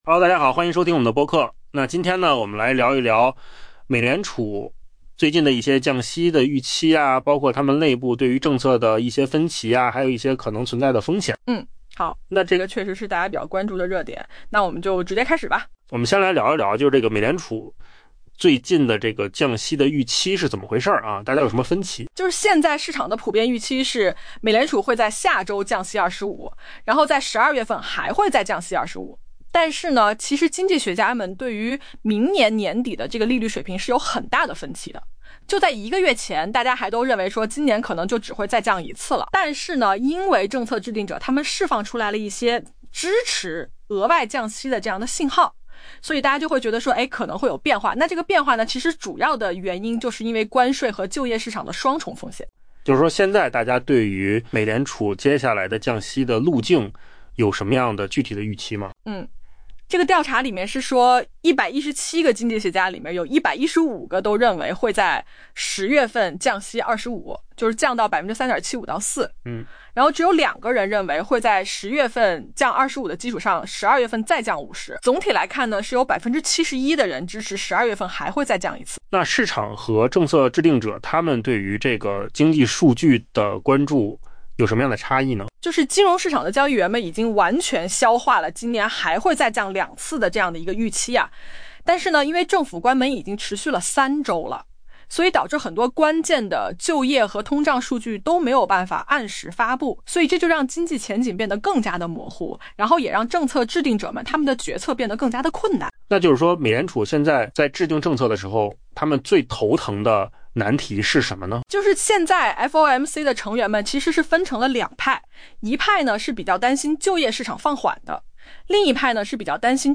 【文章来源：金十数据】AI播客：换个方
AI 播客：换个方式听新闻 下载 mp3 音频由扣子空间生成 路透社一项经济学家调查显示，美联储下周将降息 25 个基点，并在 12 月再次降息；但对于明年年底利率水平，受访经济学家仍存在严重分歧。